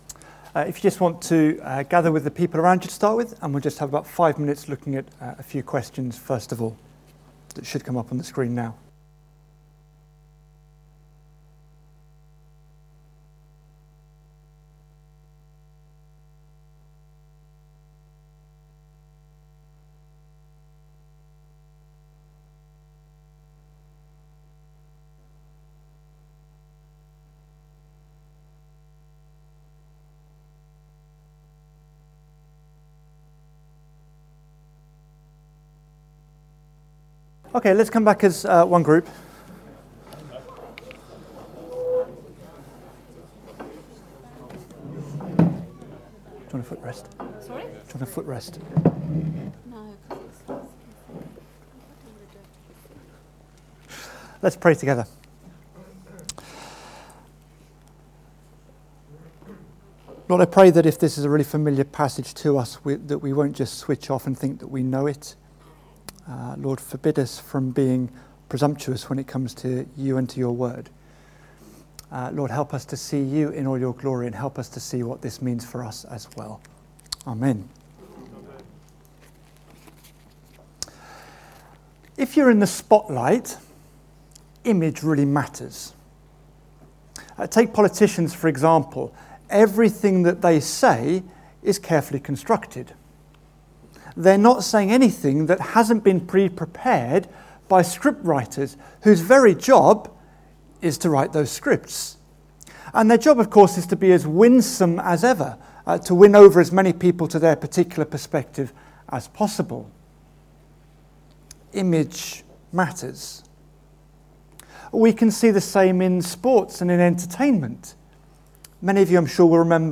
Single Sermon | Hope Church Goldington